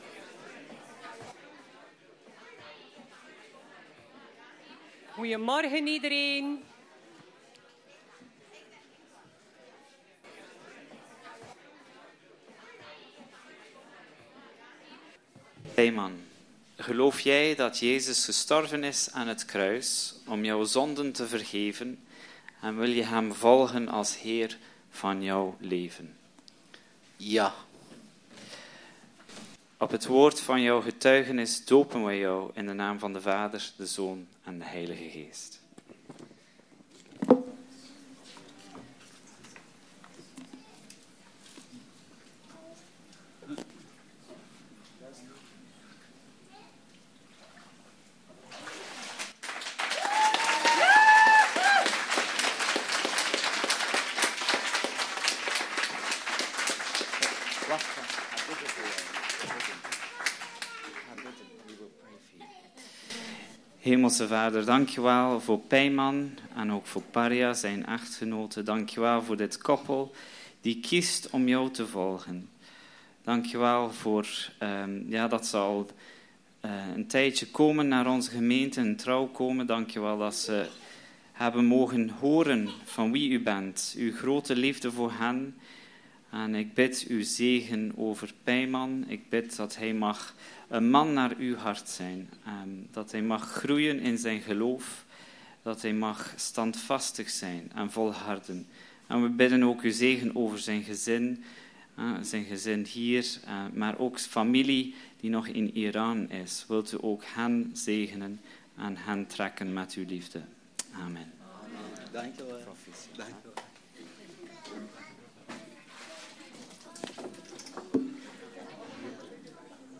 DOOPDIENST PINKSTEREN
In deze opname kunt u horen hoe 5 mensen gekozen hebben voor Jezus en om zich te laten dopen; Onze verontschuldigingen dat het eerste deel van de dienst-waaronder de preek- door een technisch mankement niet in de opname aanwezig is.